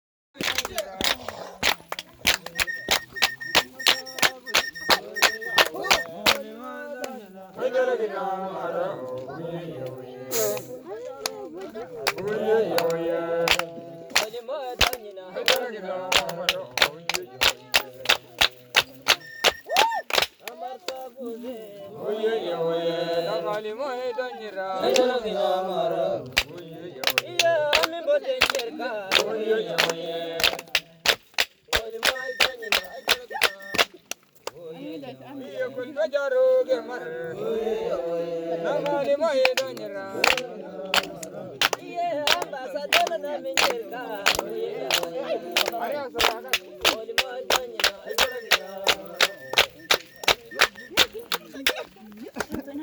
Cantos hamer hombres 2
Cantos-hamer-hombres-2.m4a